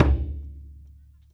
SingleHit_QAS10778.WAV